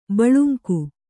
♪ baḷunku